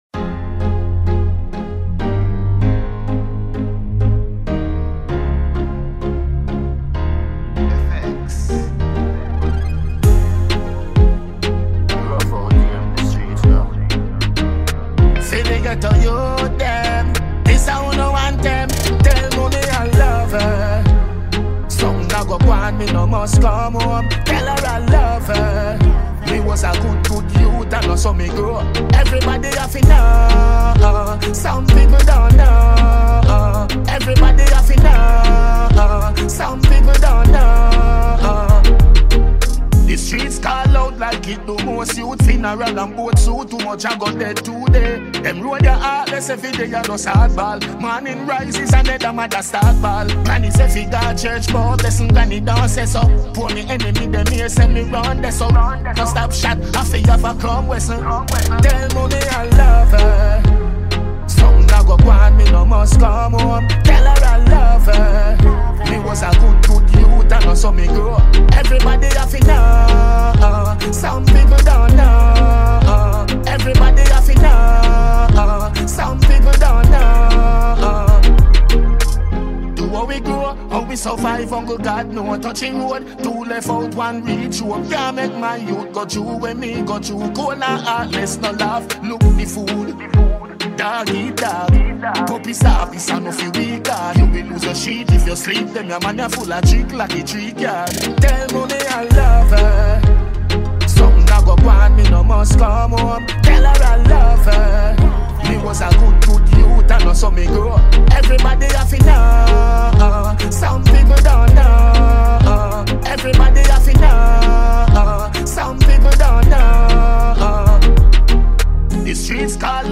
Dancehall Music
Jamaica dancehall